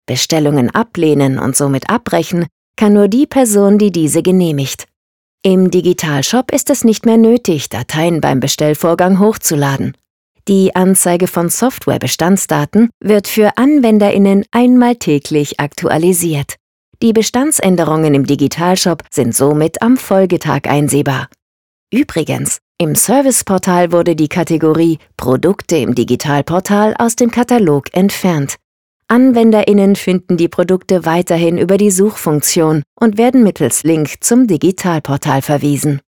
Professionelle Sprecherin mit eigenem Tonstudio, Stimmlage mittel bis hoch, Stimmalter 20-45.
Sprechprobe: eLearning (Muttersprache):
german female speaker, professional voice actor, recording studio, commecial, documentary, voiceover, e-Learnig, stationvoice